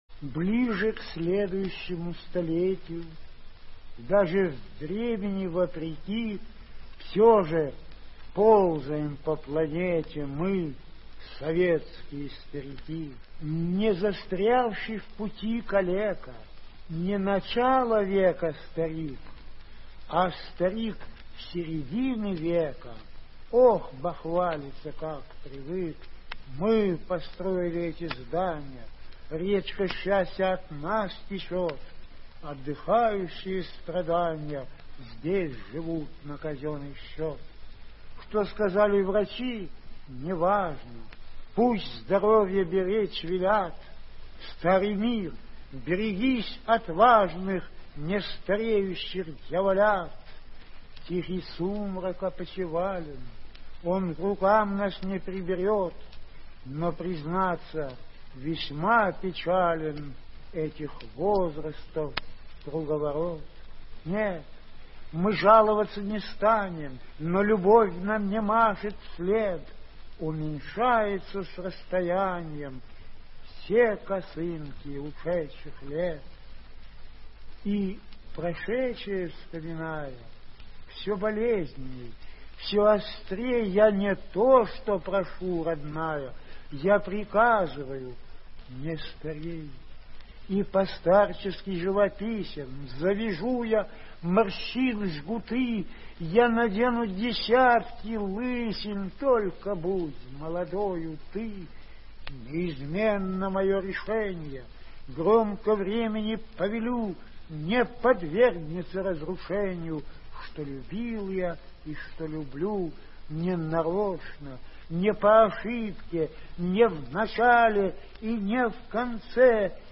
2. «Михаил Светлов – Советские старики (читает автор)» /